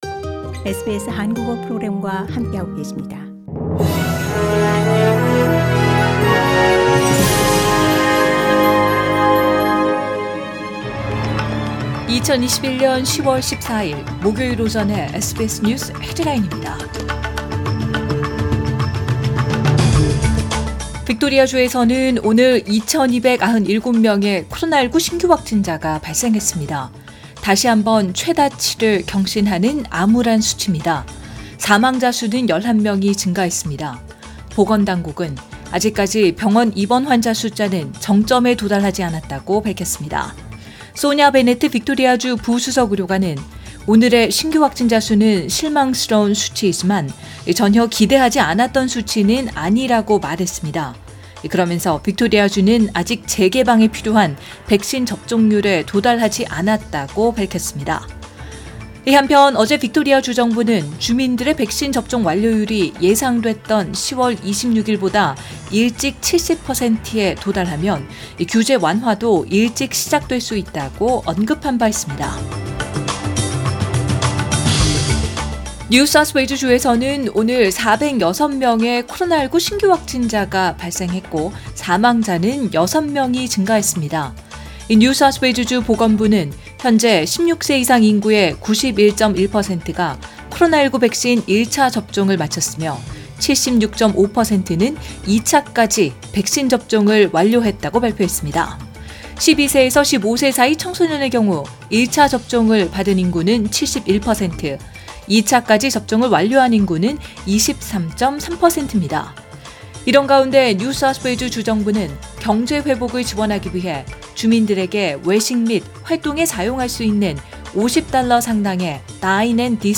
2021년 10월 14일 목요일 오전의 SBS 뉴스 헤드라인입니다.